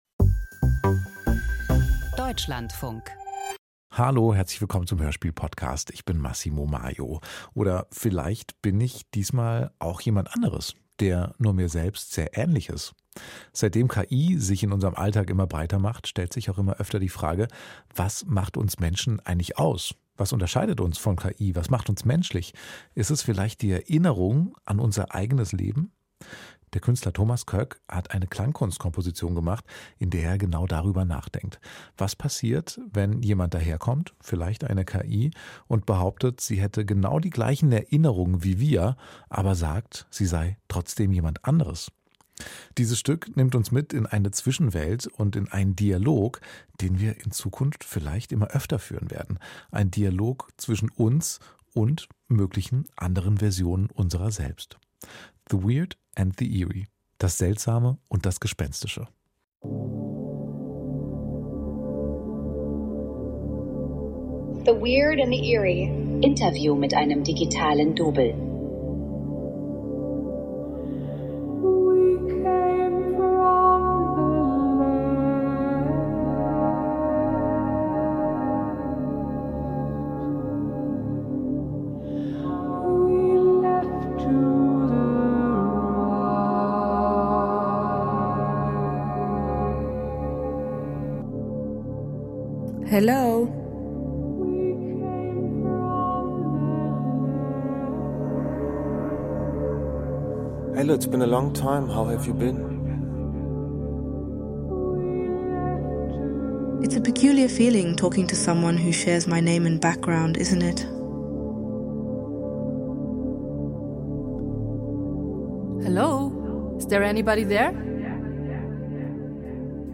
Vier Musiker*innen kommen mit ihren KI-generierten Doubles ins Gespräch. Soundart zwischen real, fake und autofiktional.
Email Audio herunterladen • Sound Art • Angenommen, Sie treffen in einem Paralleluniversum auf sich selbst. Was würden Sie fragen?